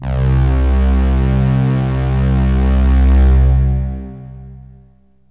SYNTH LEADS-1 0016.wav